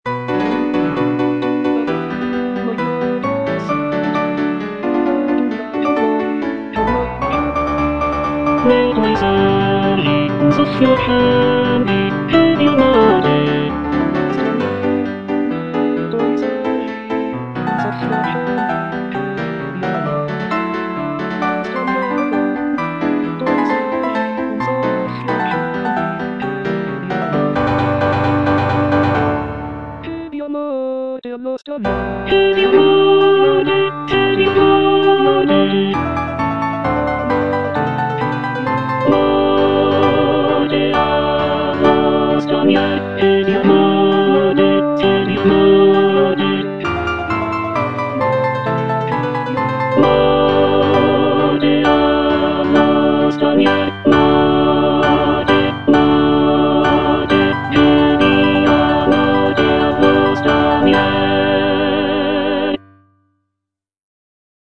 G. VERDI - DI LIETO GIORNO UN SOLE FROM "NABUCCO" Ne' tuoi servi un soffio accendi (tenor II) (Emphasised voice and other voices) Ads stop: auto-stop